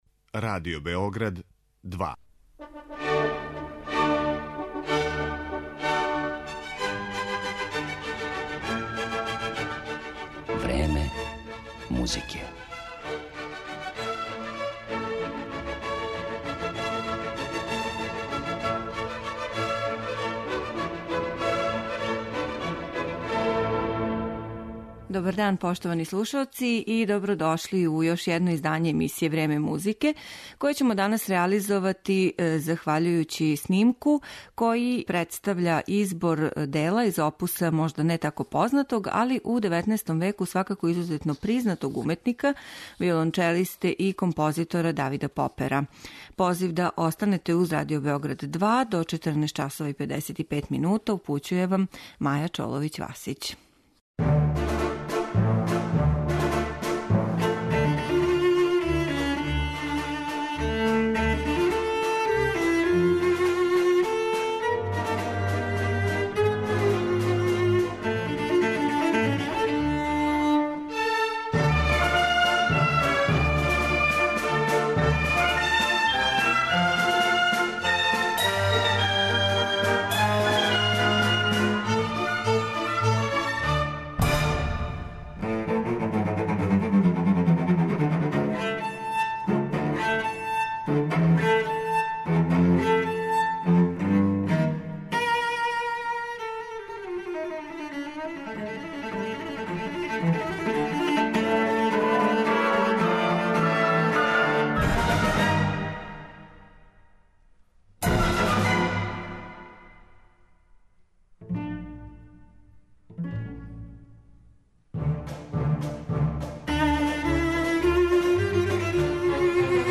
виолончелисте и композитора музике за свој инструмент